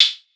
TM88 FunnyBoneHi-Hat.wav